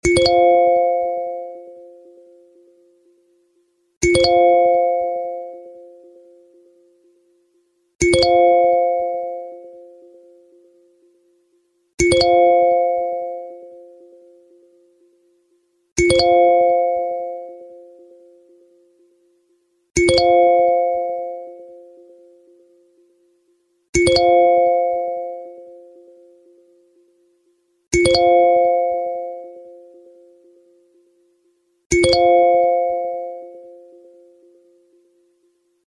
🎵🔔" Apple has refreshed its notification sounds for 2025, and the iconic **chord sound** just got an update! Listen to the latest iPhone notification tones and experience the fresh, modern sound of iOS.